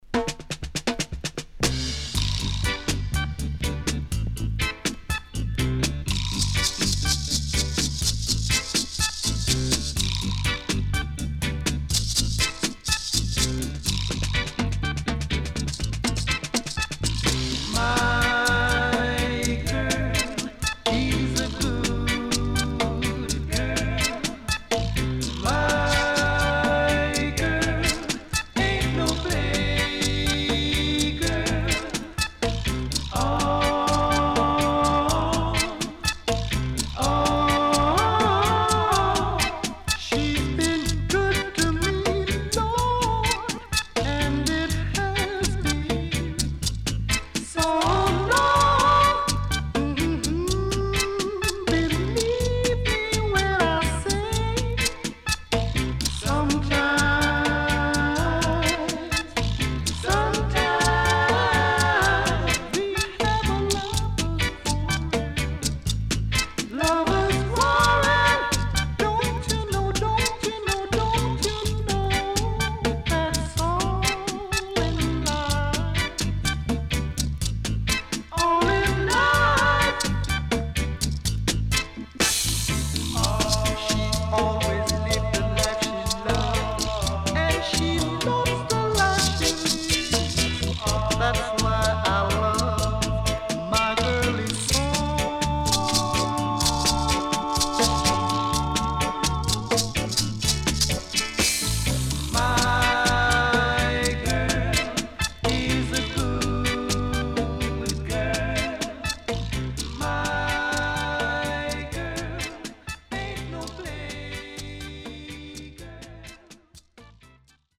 HOME > DISCO45 [VINTAGE]  >  STEPPER
SIDE A:少しチリノイズ入ります。